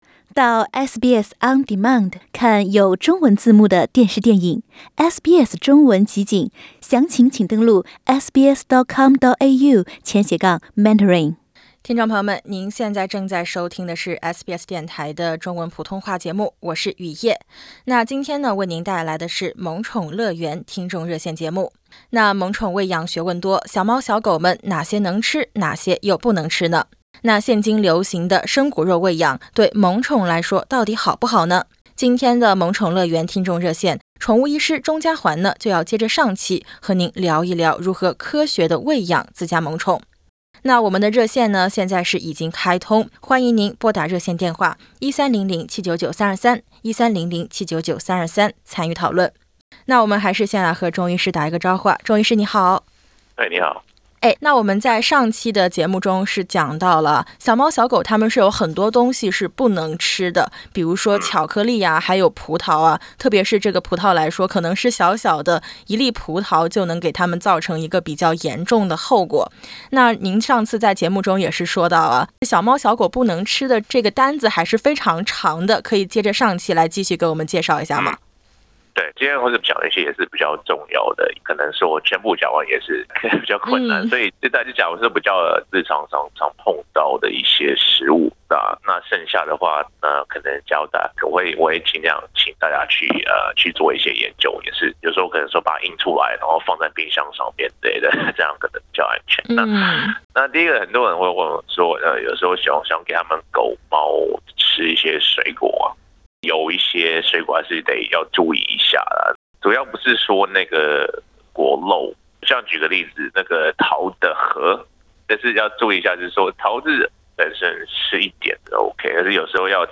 欢迎点击图片音频，收听完整采访。